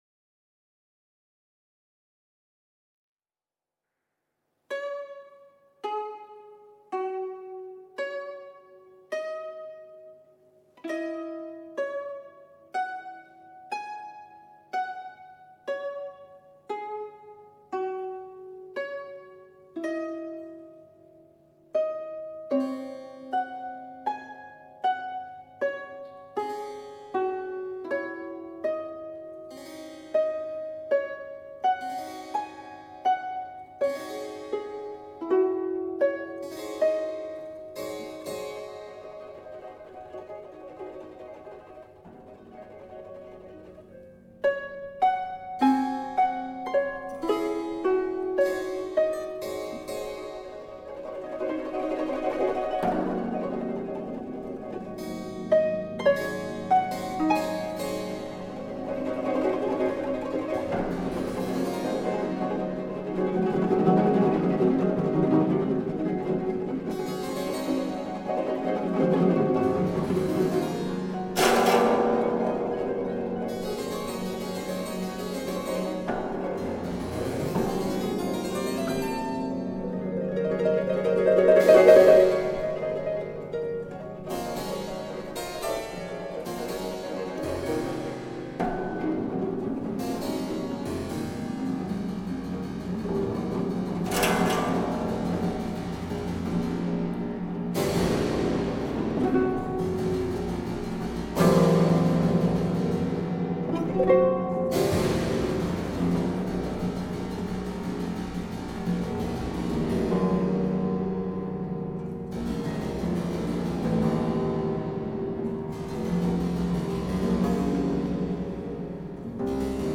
for violin, violoncello and accordion